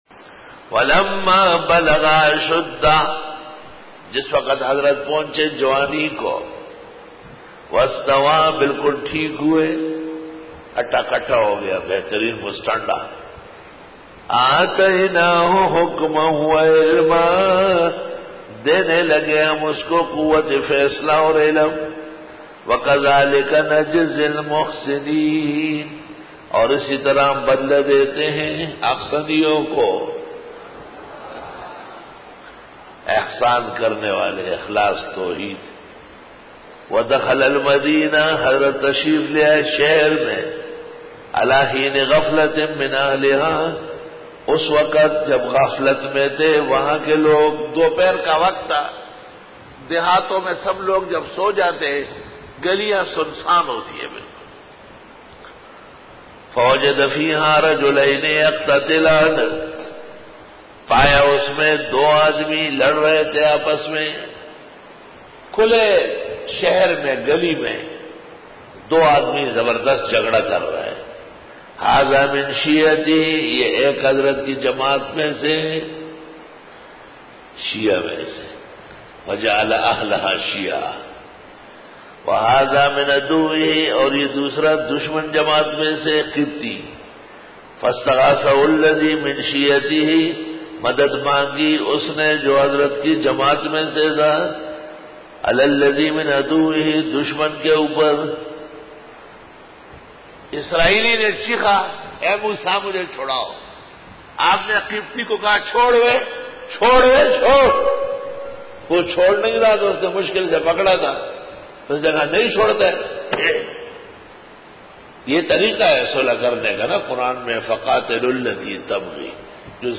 سورۃ القصص رکوع-02 Bayan